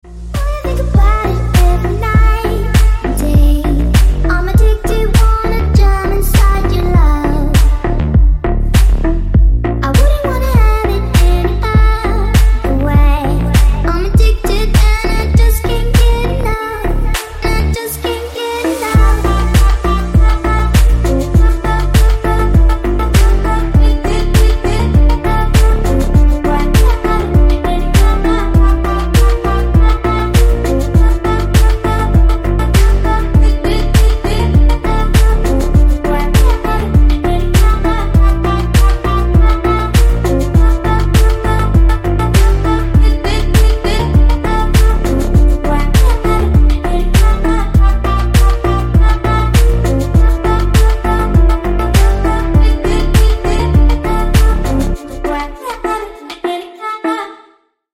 Танцевальные рингтоны
Громкие рингтоны